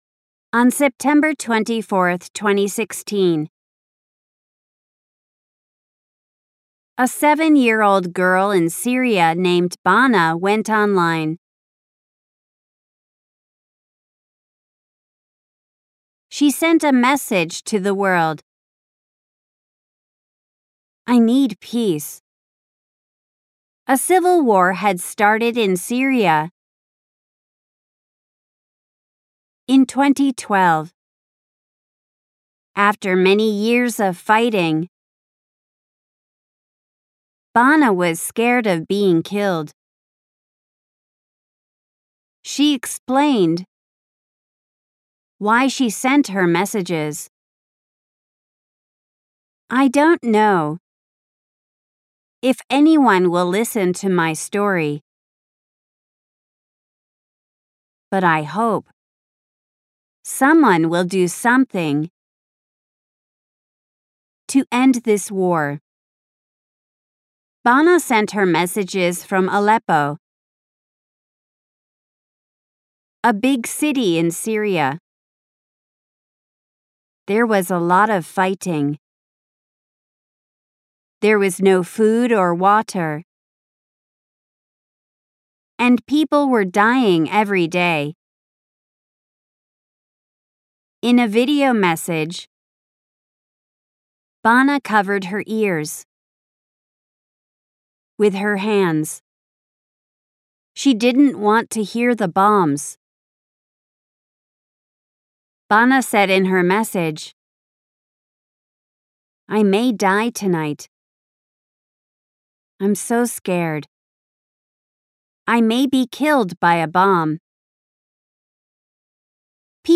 本文音声
「ポーズ付き」・・・本文をフレーズごとに区切り，リピートできるポーズを入れた音声です。